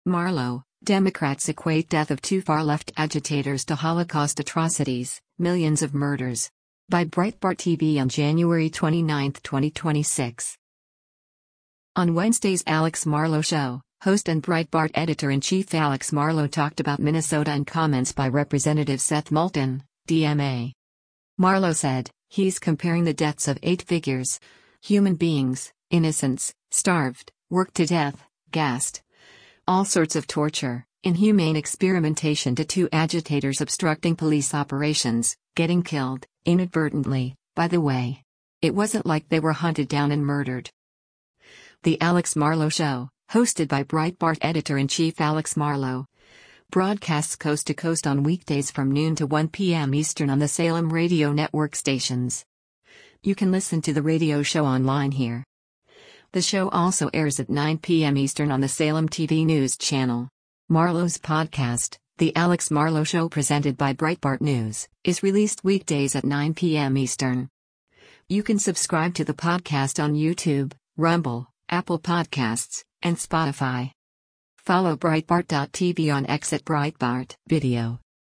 The Alex Marlow Show, hosted by Breitbart Editor-in-Chief Alex Marlow, broadcasts coast to coast on weekdays from noon to 1 p.m. Eastern on the Salem Radio Network stations.